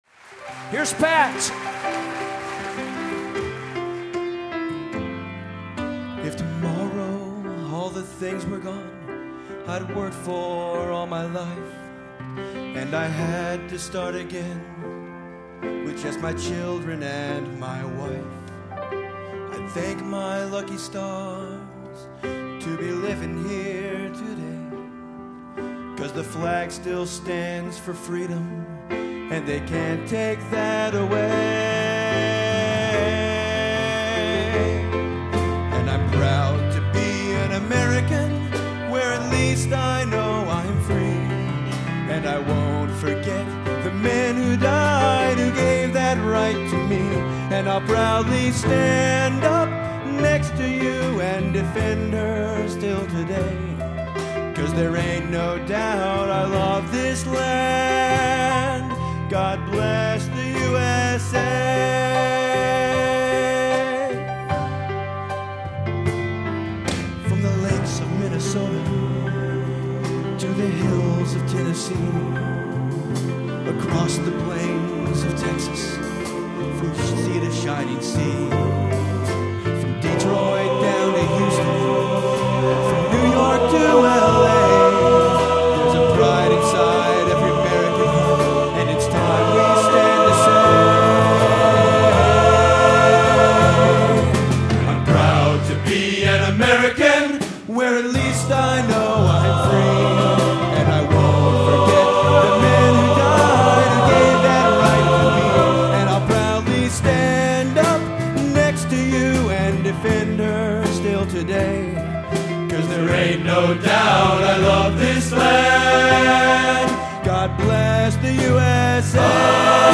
Location: Northrop High School, Fort Wayne, Indiana
Genre: Patriotic | Type: Solo